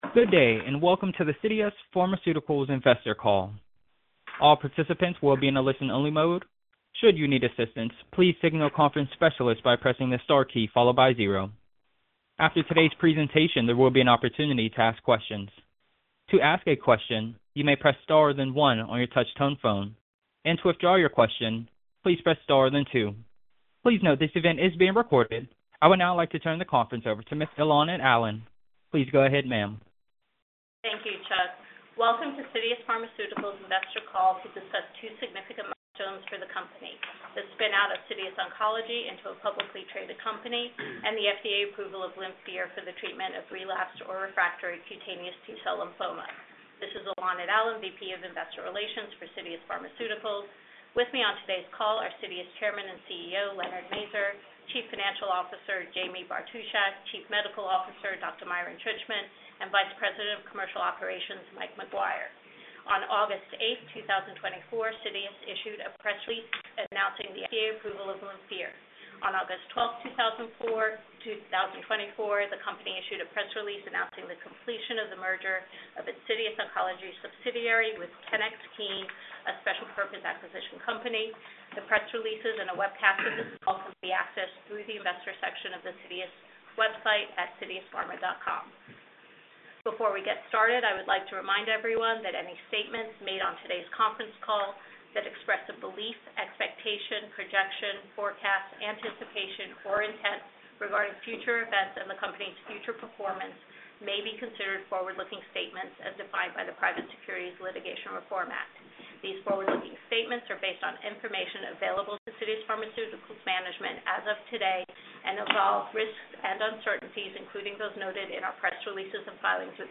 Investor Call